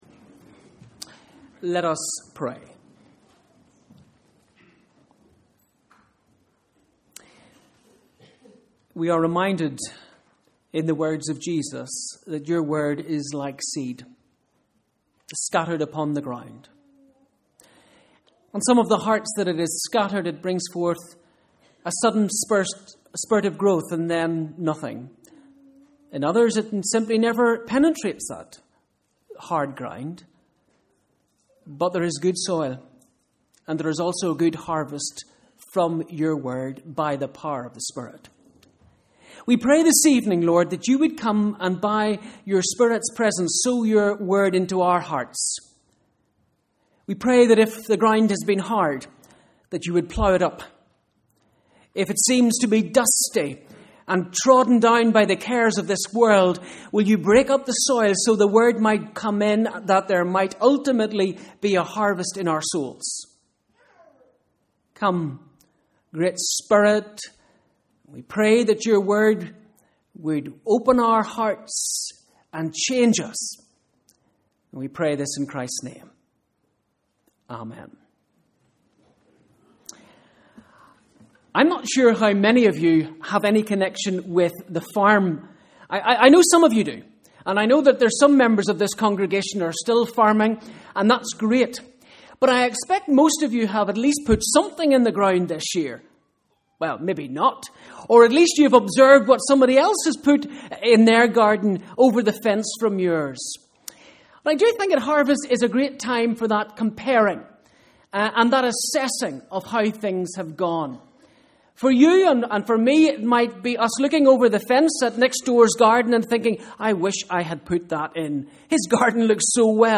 Bible Reading: Luke 13 v 1-9 Evening Service: Sunday 20th October (Harvest)